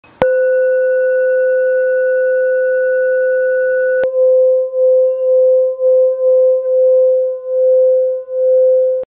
Why Choose Our Singing Bowls?
• Authentic Sound Quality: Our bowls are expertly tuned to produce rich, harmonious tones that enhance meditation, relaxation, and sound healing practices, promoting a deeper connection to your spiritual journey.
Sound-That-Heals-432-hz-Tibetan-Meditation-Music-Sound-Bath-Meditation-Healing-Frequencies.mp3